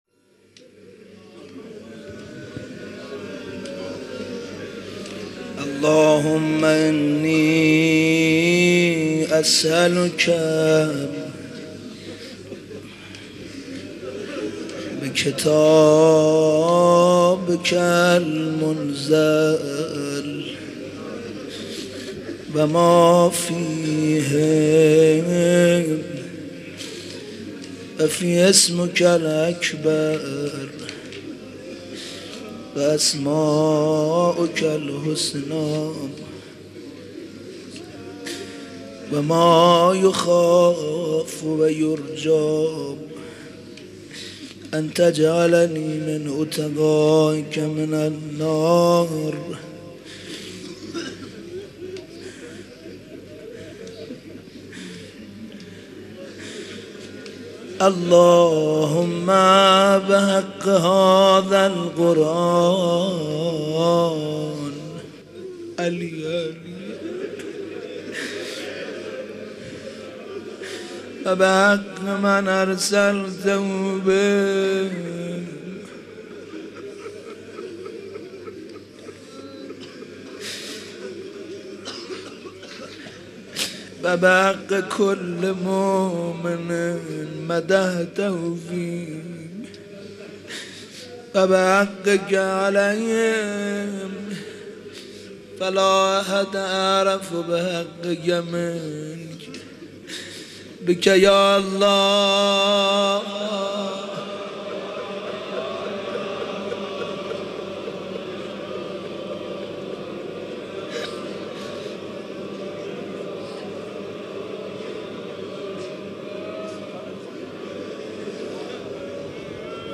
مراسم احیا